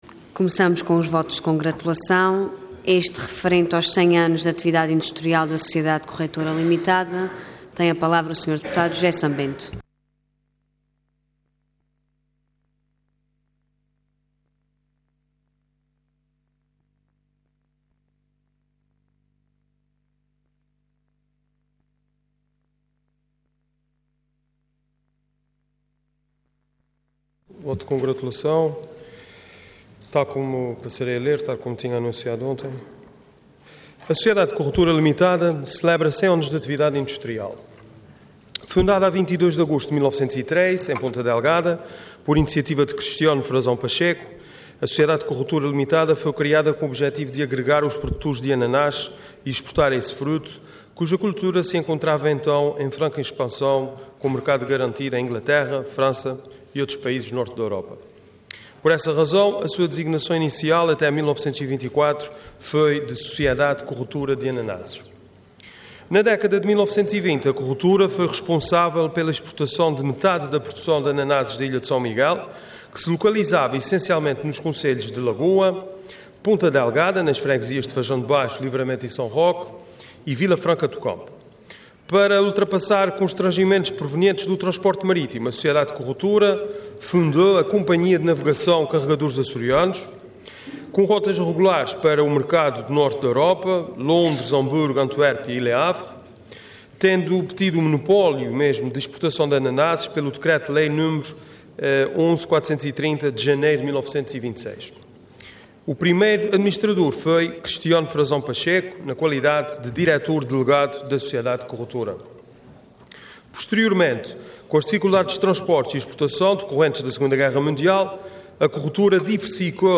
Intervenção Voto de Congratulação Orador José San-Bento Cargo Deputado Entidade PS